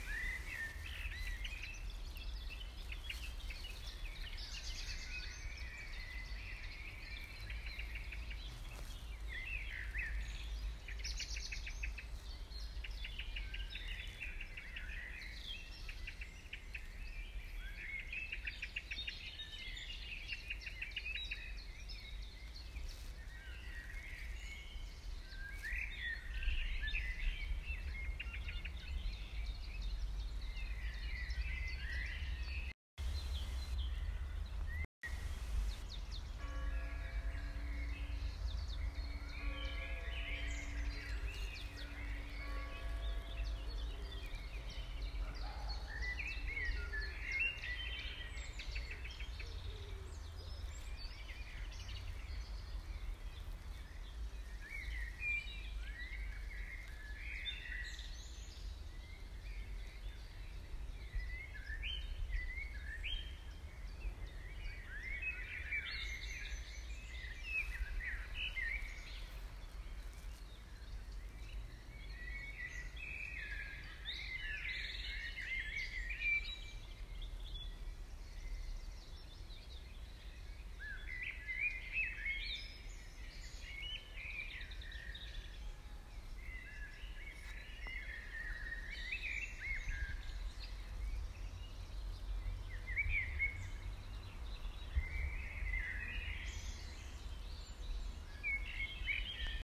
Wald:
vocc88gel-park.m4a